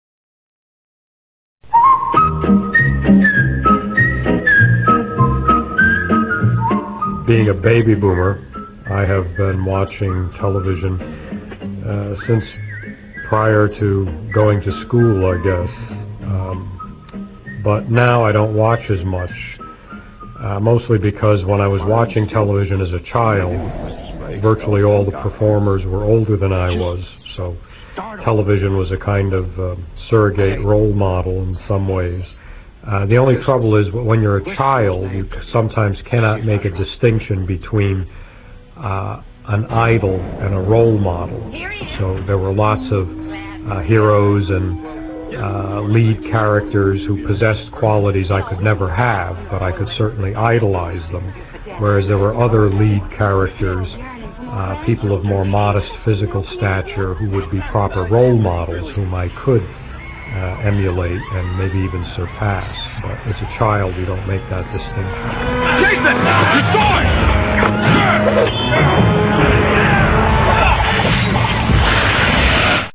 (edited interview)